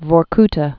(vôr-ktə, vôrk-tä)